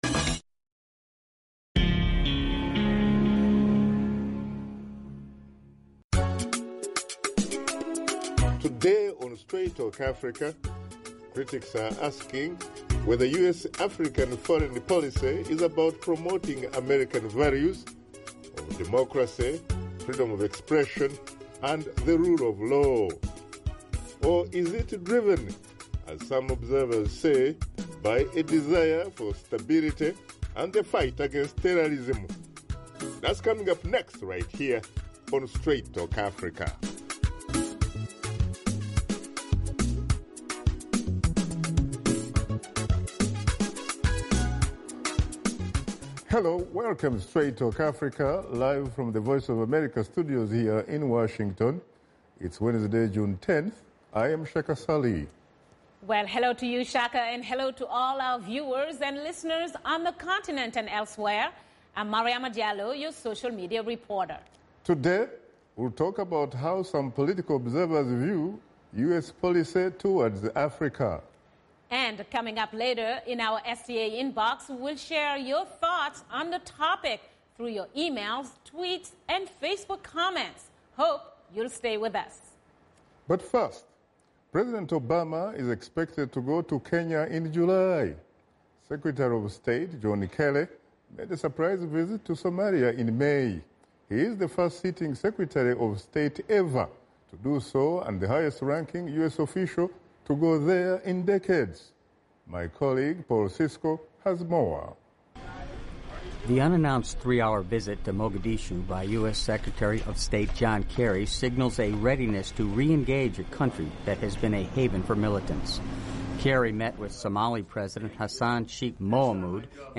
Washington Studio Guests